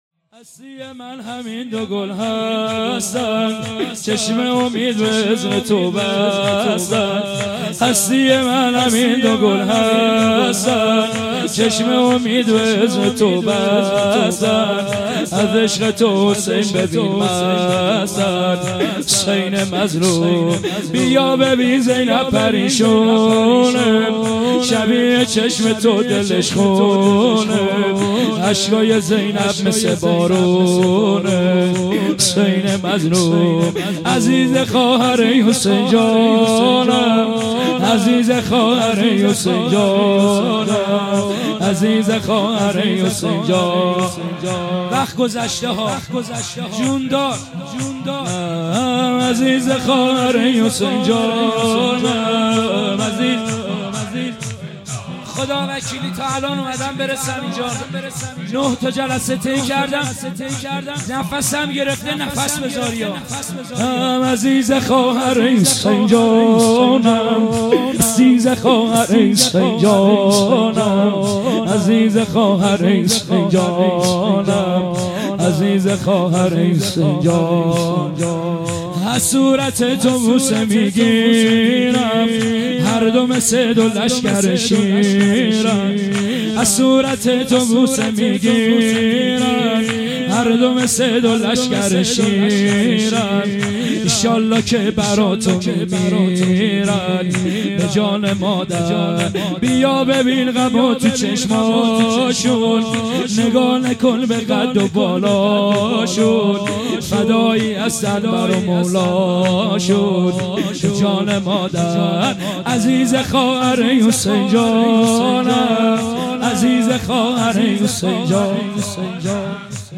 خیمه گاه - هیئت انصارالمهدی(عج) درچه - شور | هستی من همین دو گل هستند